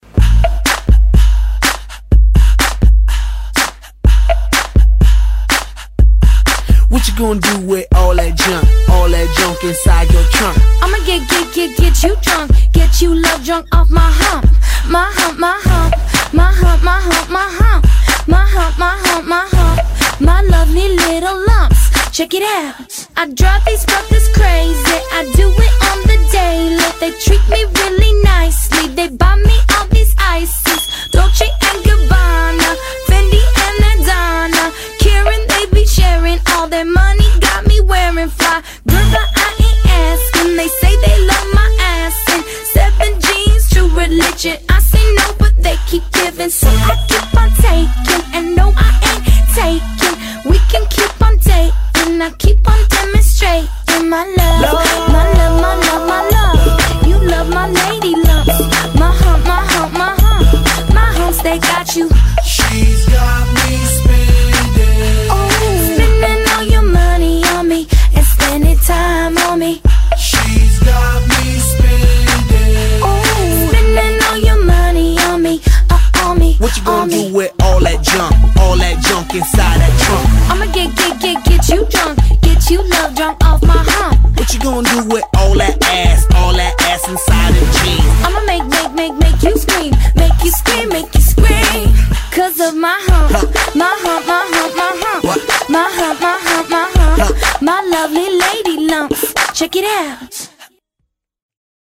BPM124--1
Audio QualityPerfect (High Quality)